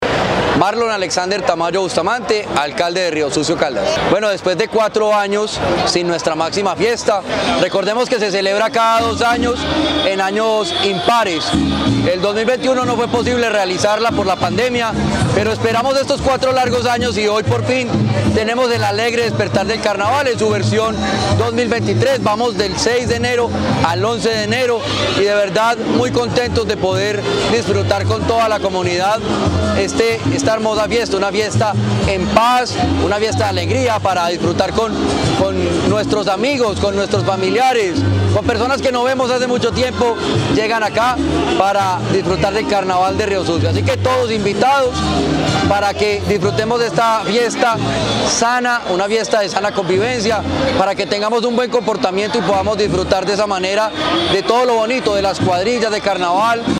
Alcalde de Riosucio Caldas
FULL-Alcalde-de-Riosucio-Marlon-Alexander-Tamayo.mp3